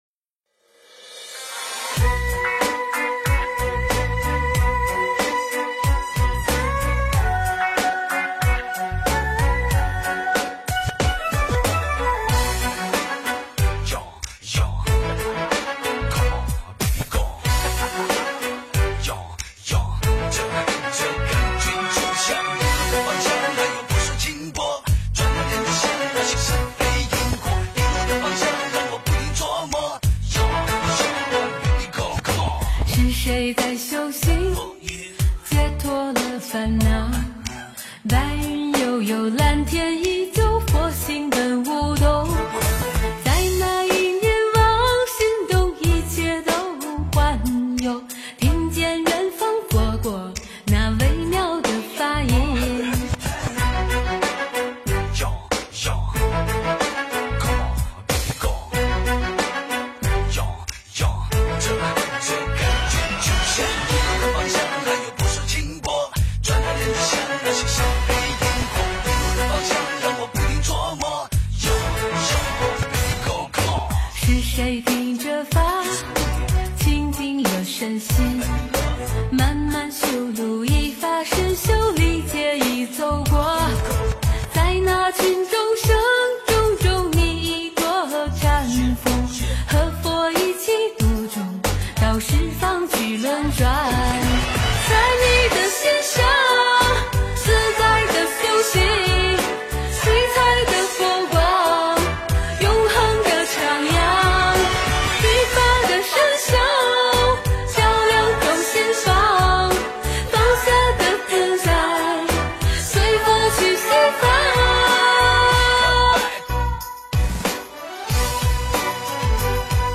佛教音乐
标签: 佛音诵经佛教音乐